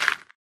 gravel2.ogg